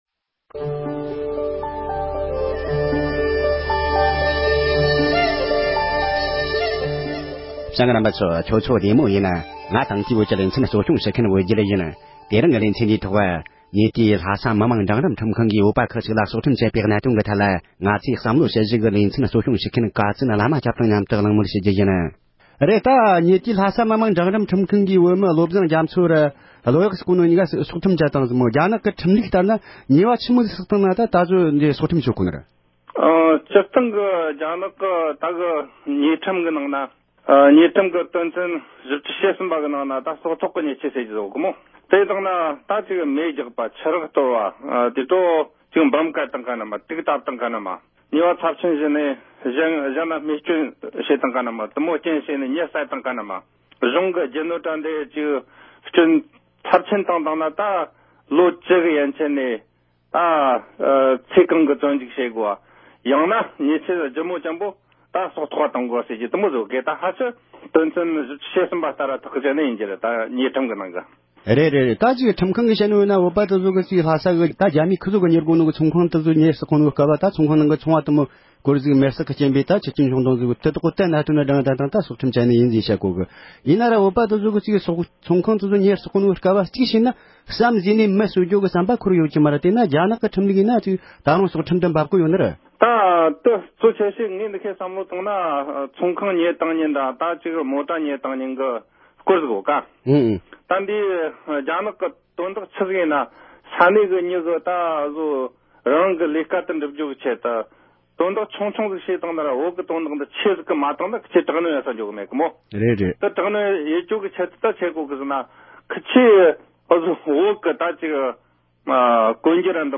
ཉེ་ཆར་རྒྱ་ནག་གཞུང་གིས་བོད་མི་ཁག་ཅིག་ལ་སྲོག་ཁྲིམས་བཅད་པའི་གནད་དོན་ཐད་གླེང་མོལ།
སྒྲ་ལྡན་གསར་འགྱུར།